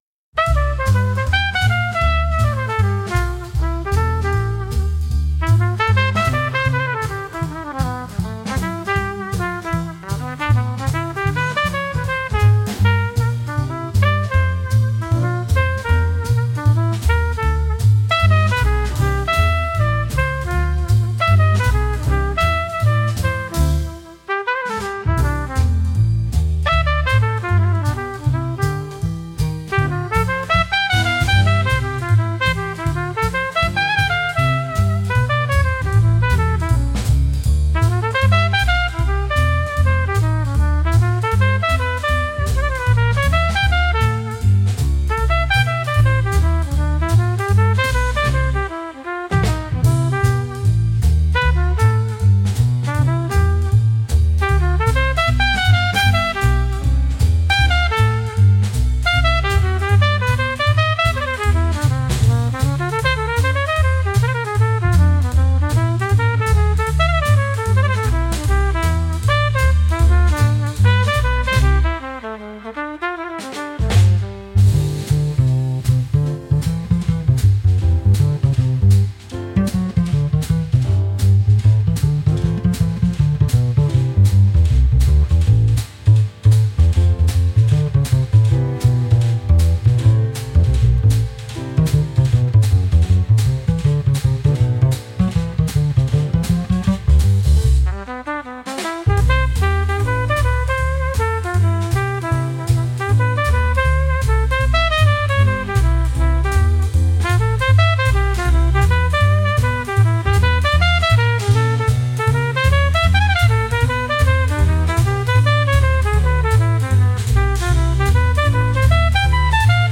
カフェで使える落ち着いたジャズです。